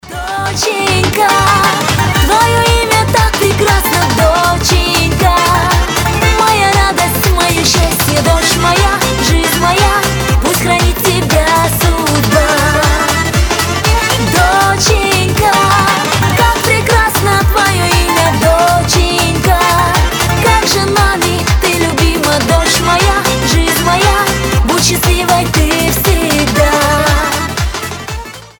• Качество: 320, Stereo
быстрые
кавказские
добрые